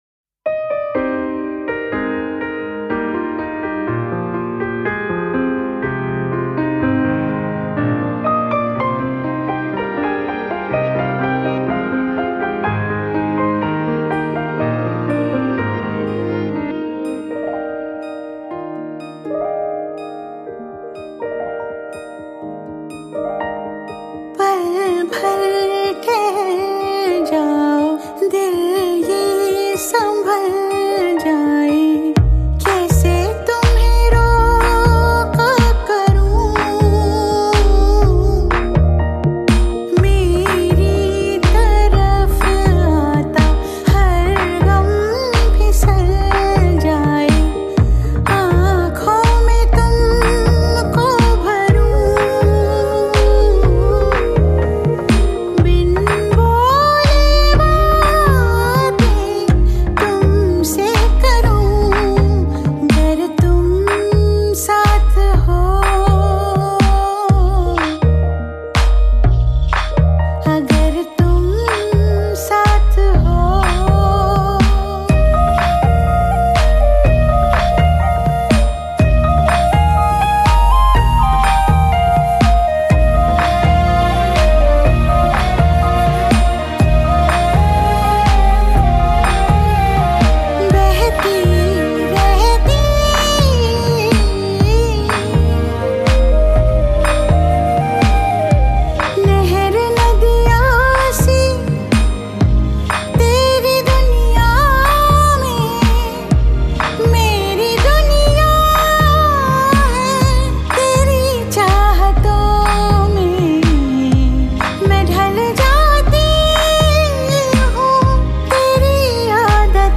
a duet song